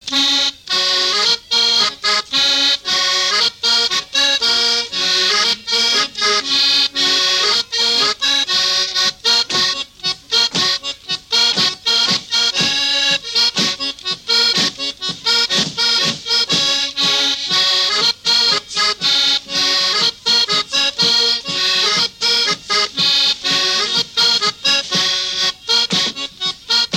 Mémoires et Patrimoines vivants - RaddO est une base de données d'archives iconographiques et sonores.
danse : polka lapin
Pièce musicale inédite